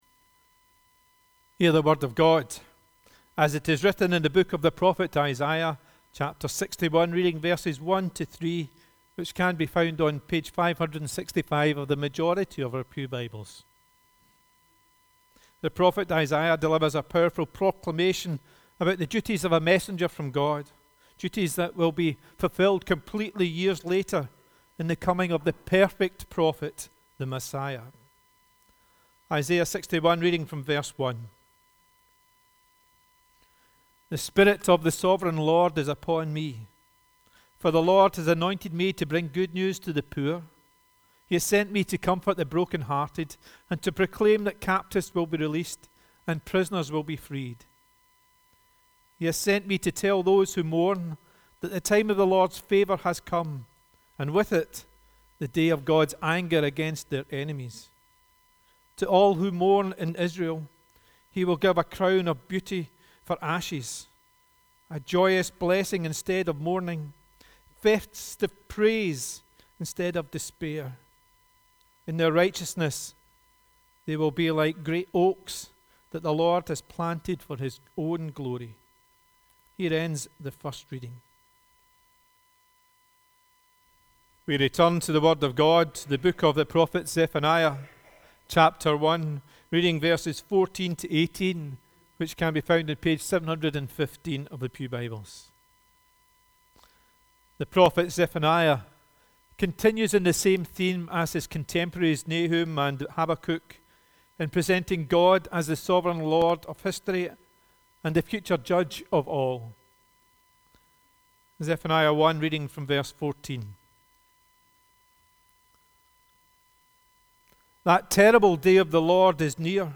Zephaniah - Uddingston Burnhead Parish Church